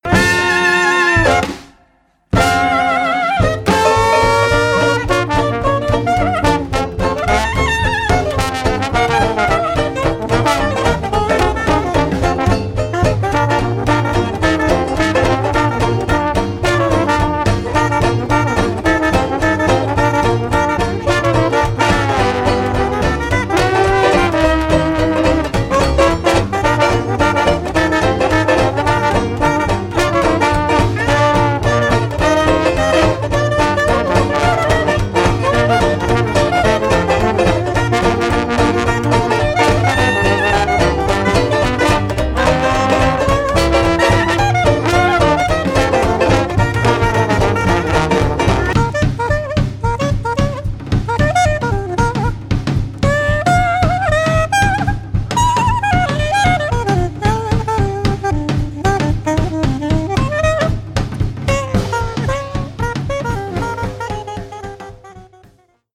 saxophone soprano, chant
trombone
clarinette
banjo, chant
contrebasse
batterie